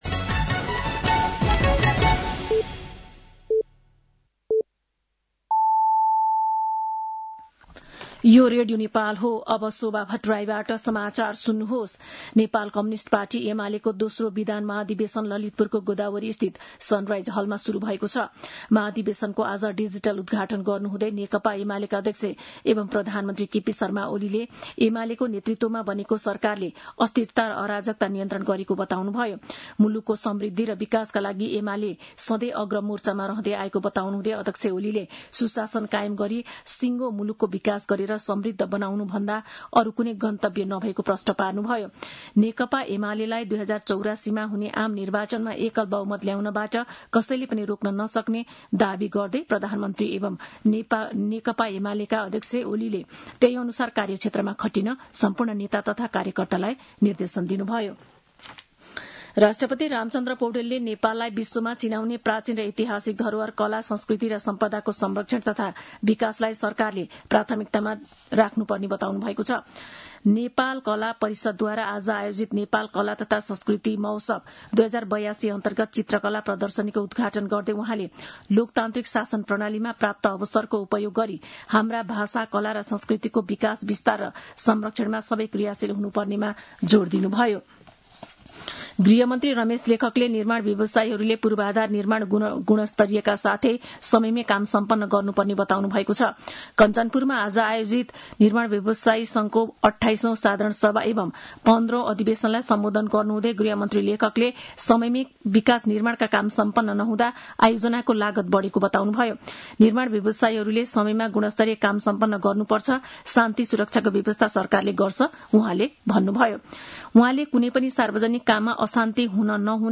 साँझ ५ बजेको नेपाली समाचार : २० भदौ , २०८२
5-pm-nepali-news-5-20.mp3